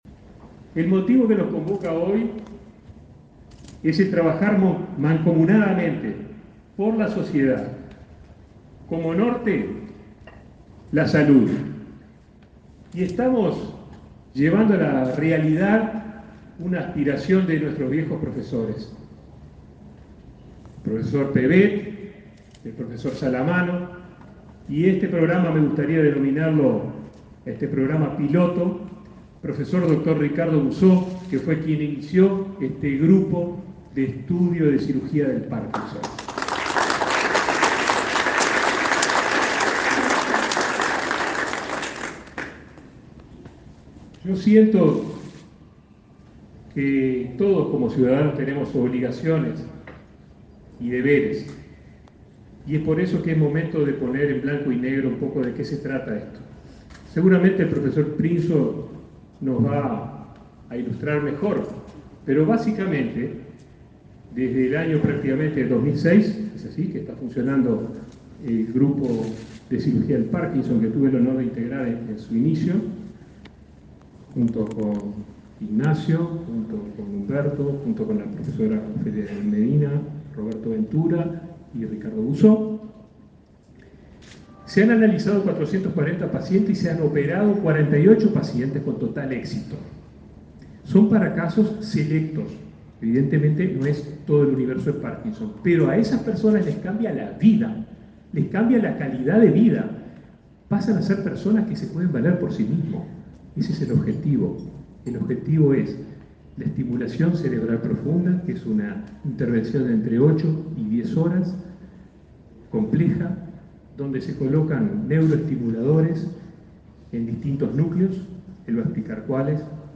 Palabras de autoridades en el Hospital de Clínicas